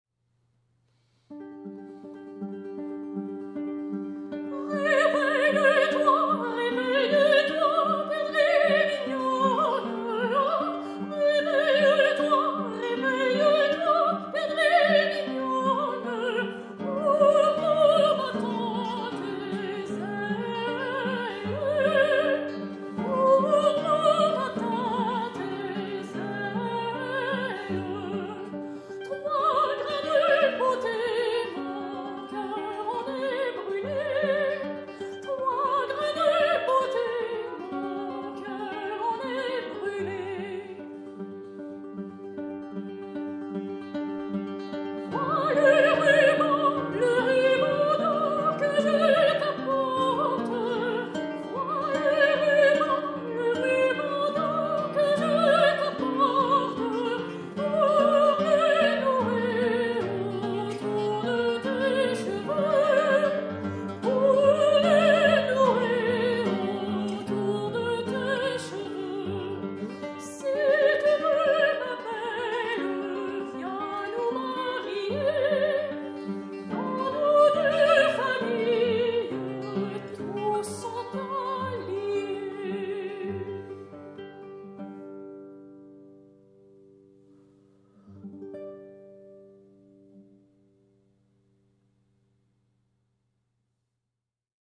guitariste
soprano
Ils se consacrent à l’interprétations du répertoire classique et contemporain, ainsi qu’à la commande de nouvelles œuvres auprès de compositeurs et compositrices actuels, mettant en avant la complémentarité de la guitare et de la voix.